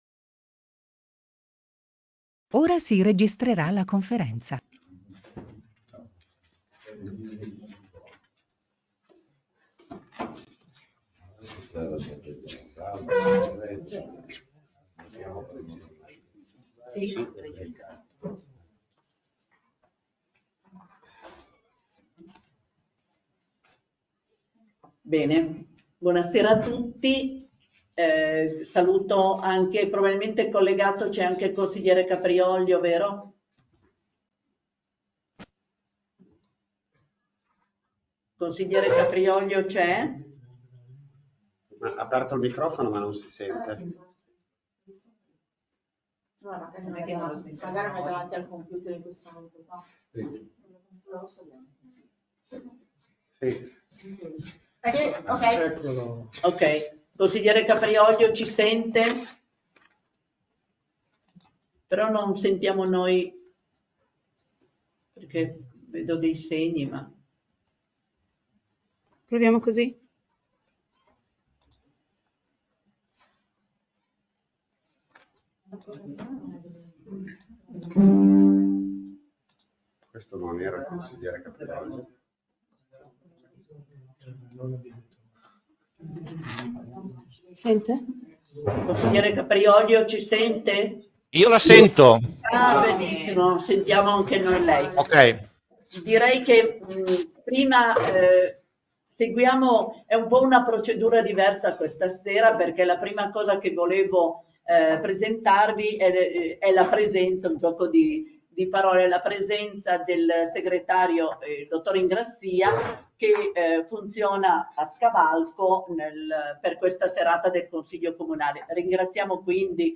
Comune di Santhià - Registrazioni audio Consiglio Comunale - Registrazione Seduta Consiglio Comunale 23/04/2025